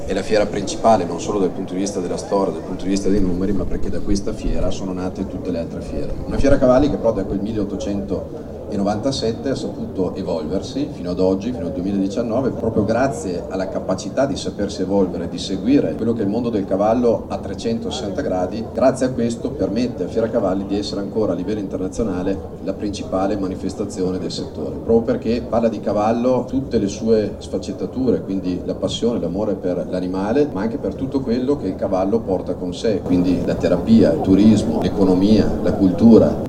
Presenti all’inaugurazione, intervistati dalla nostra corrispondente
il sindaco di Verona Federico Sboarina